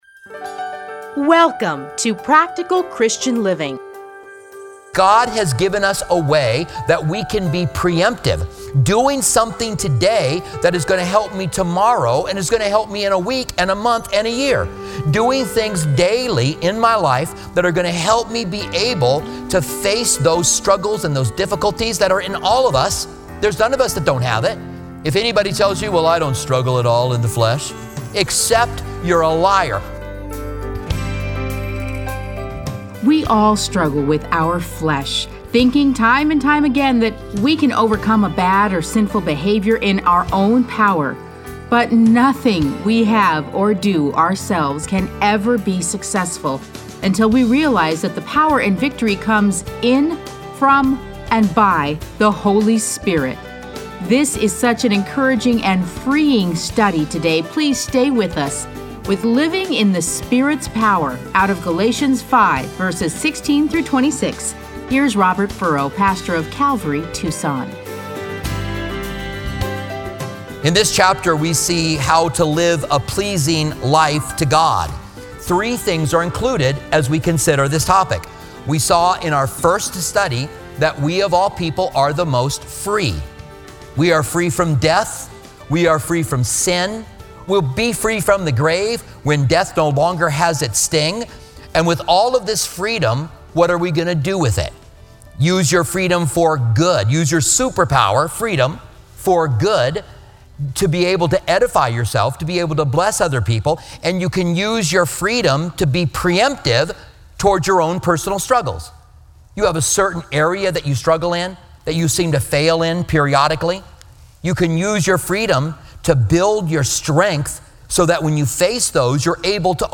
Listen to a teaching from Galatians 5:16-26.